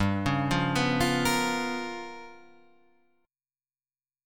G7#9b5 chord